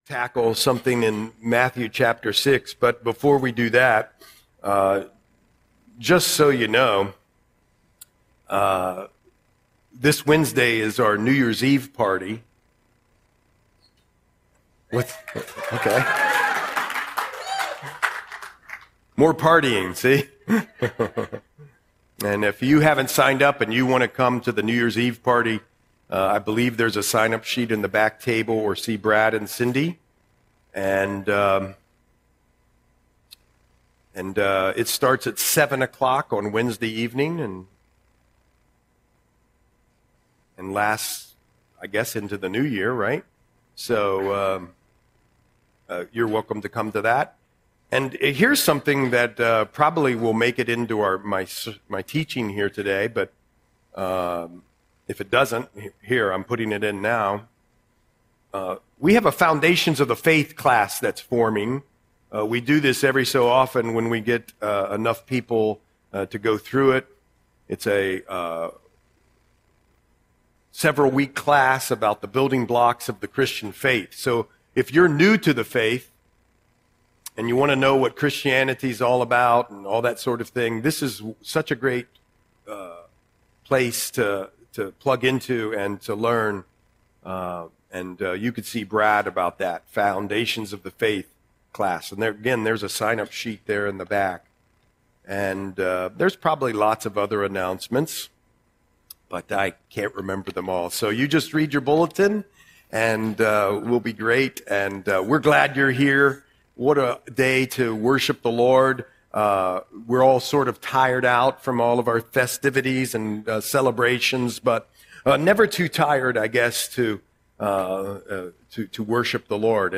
Audio Sermon - December 28, 2025